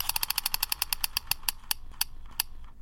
На этой странице представлены звуки колеса фортуны в разных вариациях: от классического вращения до эффектных фанфар при выигрыше.
Вращение барабана для выигрыша призовых очков